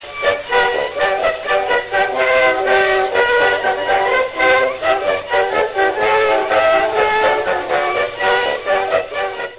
Wax cylinder excerpts . . .
•  Peerless Orchestra, 1904